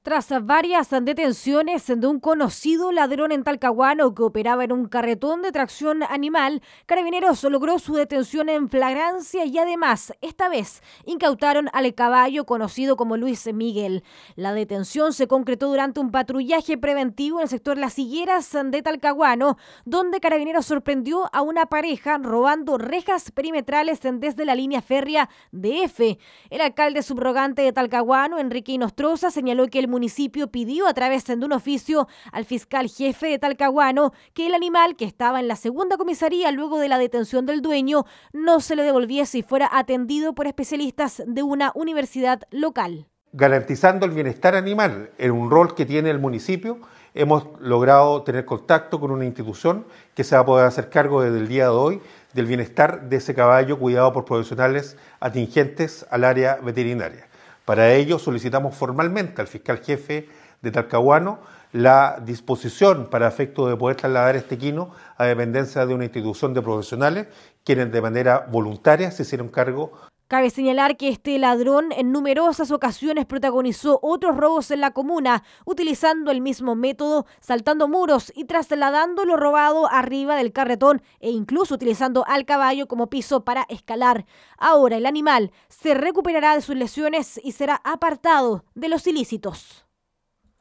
Al respecto, el alcalde (s) Enrique Inostroza destacó que el caballo quedó al cuidado de profesionales y ya no podrá ser usado para cometer delitos en Talcahuano.
alcalde-talcahuano-carreta-choros.mp3